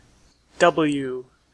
En-us-w.ogg